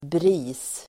Uttal: [bri:s]